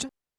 Drums_K4(36).wav